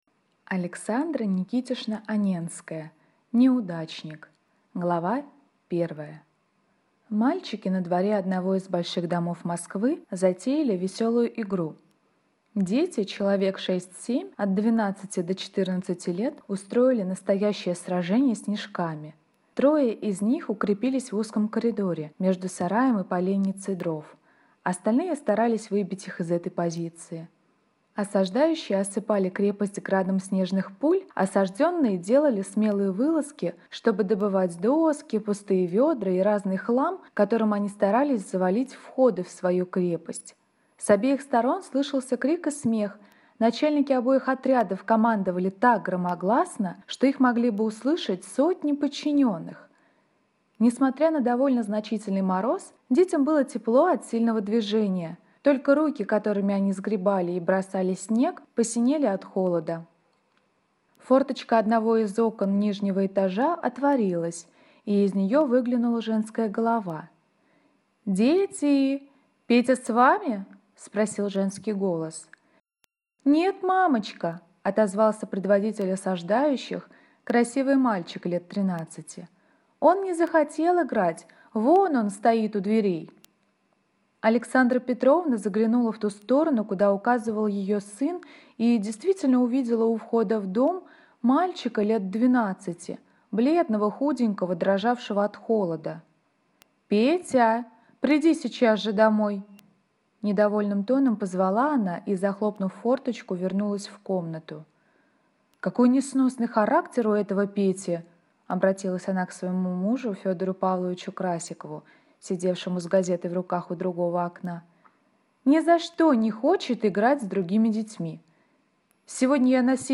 Аудиокнига Неудачник | Библиотека аудиокниг